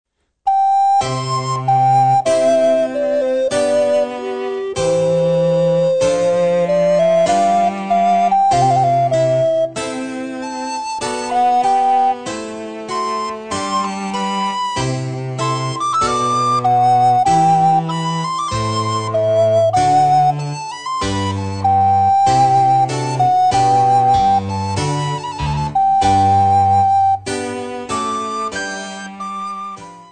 Besetzung: Altblockflöte und Basso continuo
Klassisches Blockflötenrepertoire auf Noten mit Playback-CD.